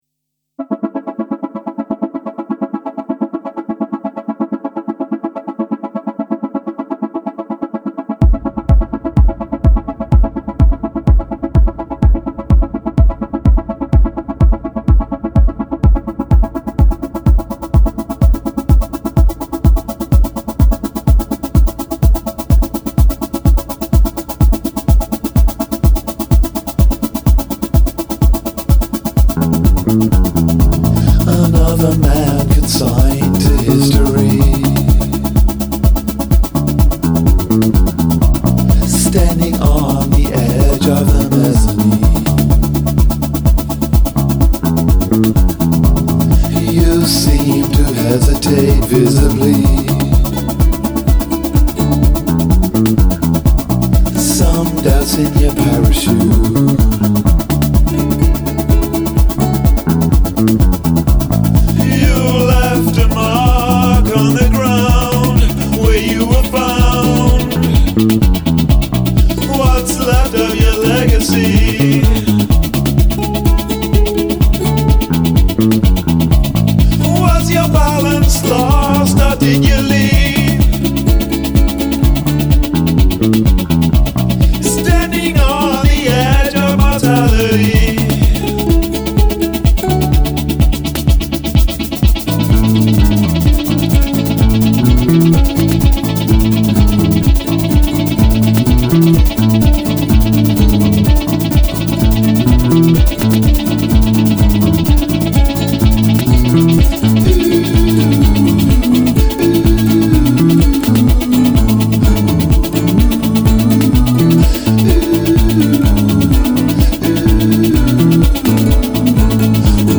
Too much monotony before and after the lyrics.